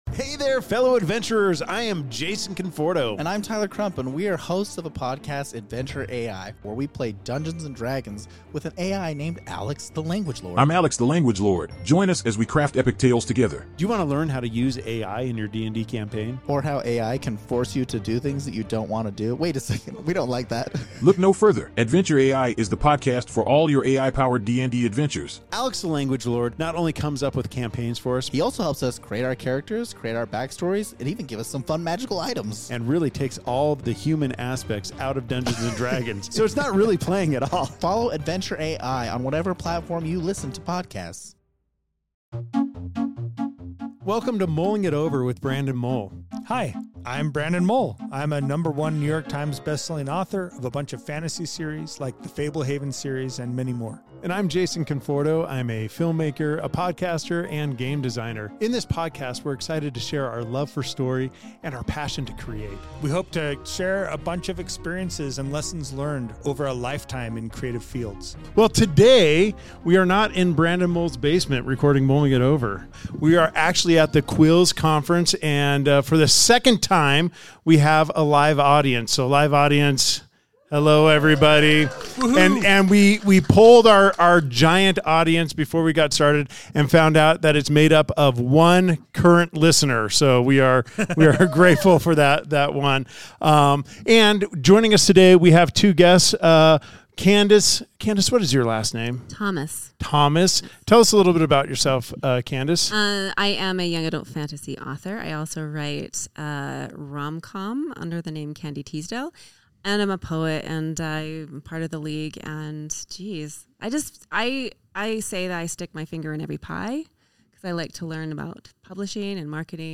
1 S12E4 - The Fade, The Pin, and The Click - Actual Play / Recap 51:36